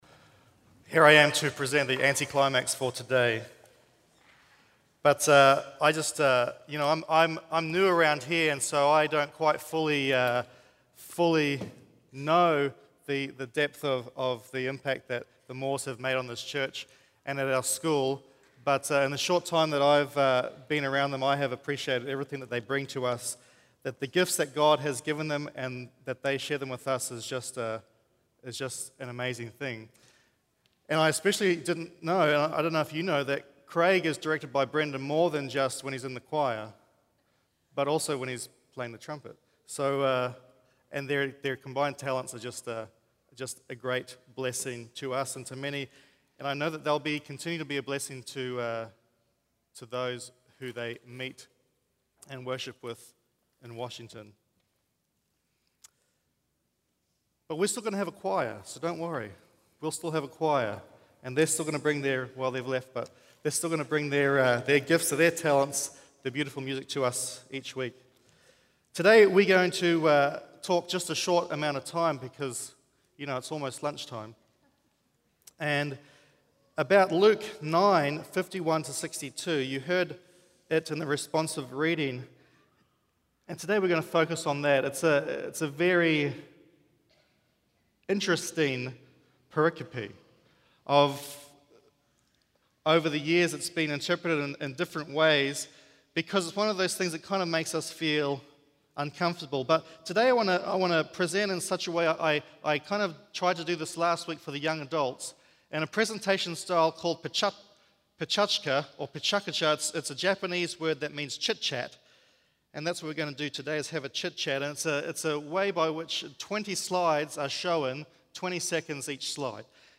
Vallejo Drive Seventh-day Adventist Church - Media Entry